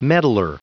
Prononciation du mot meddler en anglais (fichier audio)